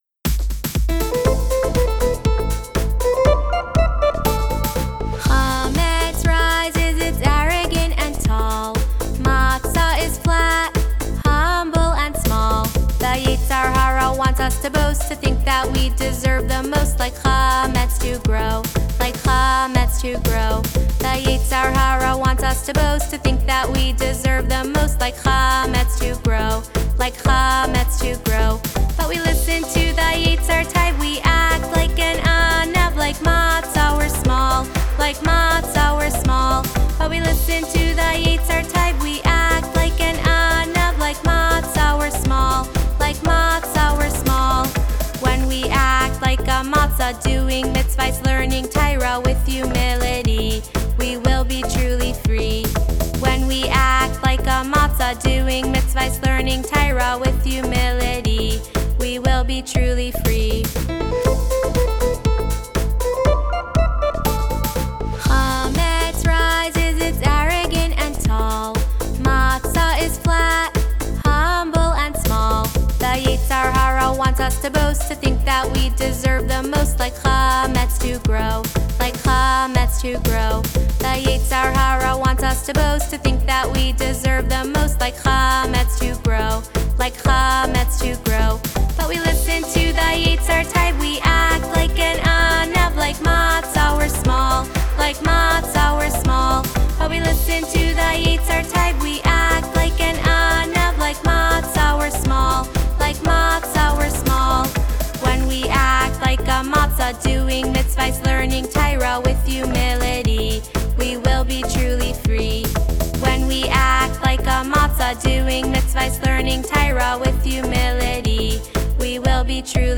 Here’s the latest Pesach song